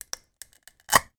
Звуки краски
Открытие небольшой банки краски или лака